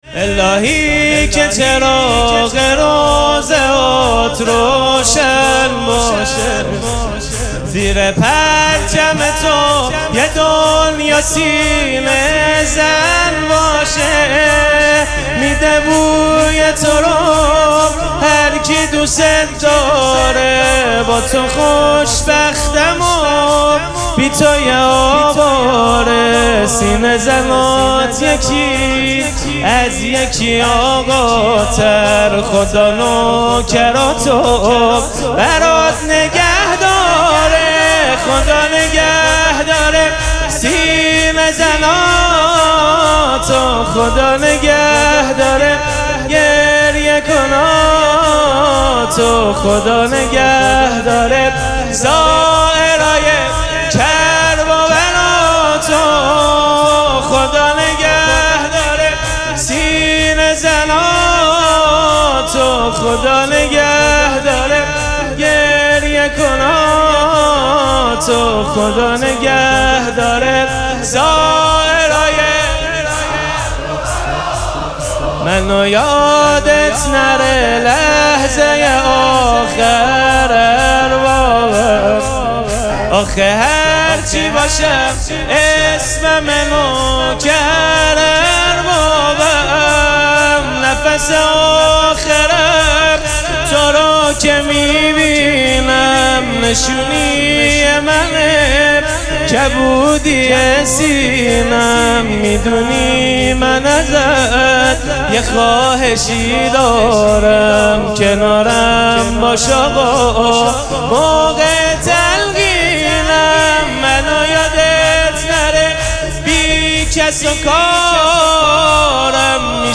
مراسم عزاداری شهادت امام صادق علیه‌السّلام
شور